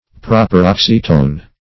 Search Result for " proparoxytone" : Wordnet 3.0 NOUN (1) 1. word having stress or acute accent on the antepenult ; The Collaborative International Dictionary of English v.0.48: Proparoxytone \Pro`par*ox"y*tone\, n. [Gr.